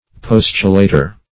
postulator - definition of postulator - synonyms, pronunciation, spelling from Free Dictionary